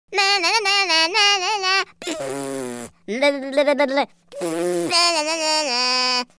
Смешные и комичные звуковые эффекты для детских видео в mp3
na-na-na-detskii-izdevatelskii.mp3